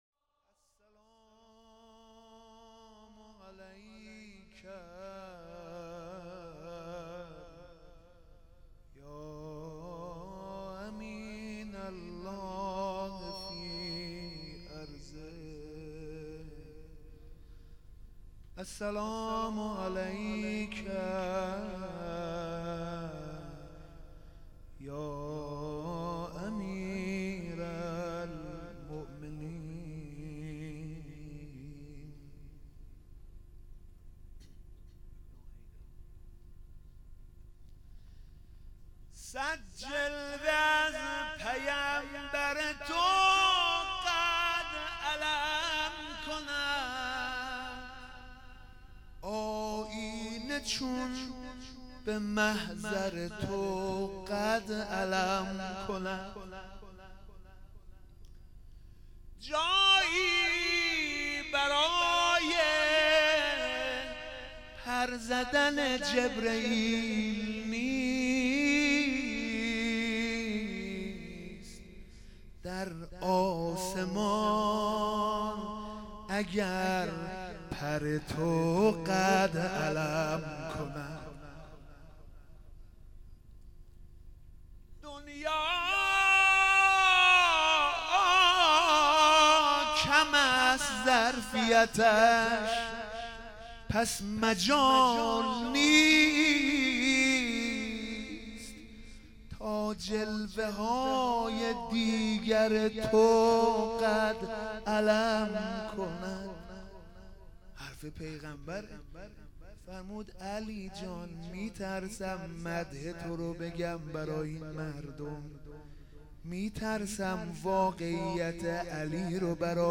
روضه-شب21.wma